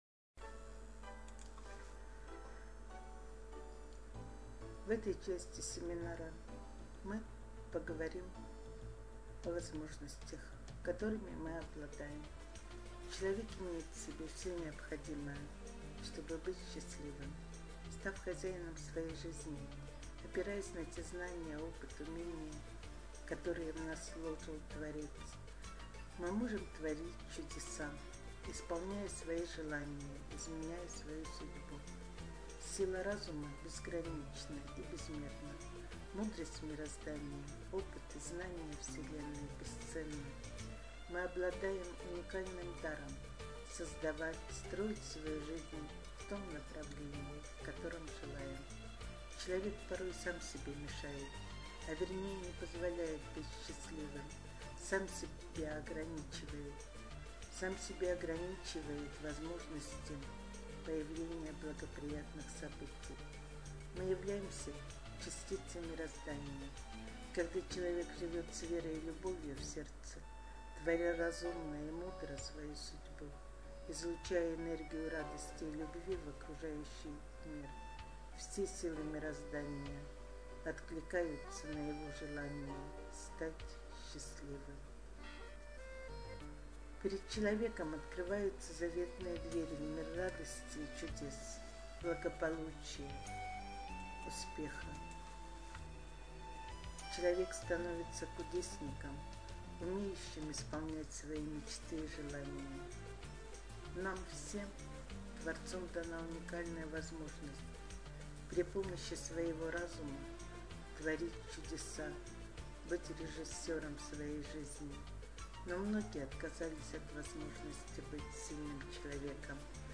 Аудиокнига: Алгоритм Успеха